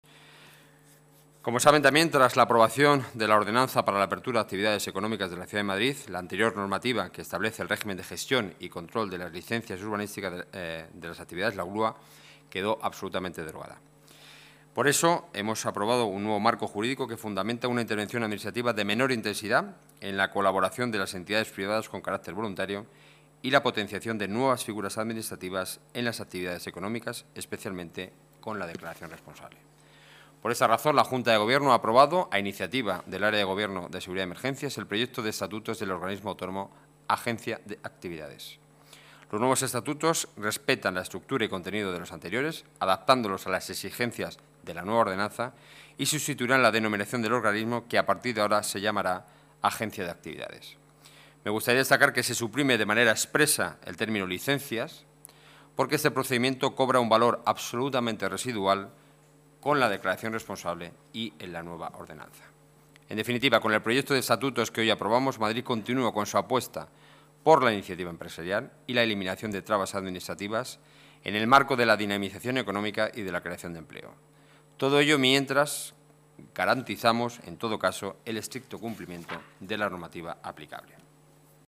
Nueva ventana:Declaraciones de Enrique Núñez, portavoz del Gobierno municipal